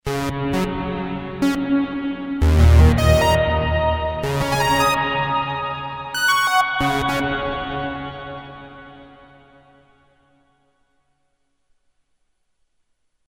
Digital Reverb (1985?)
brass demo with Roland Juno 60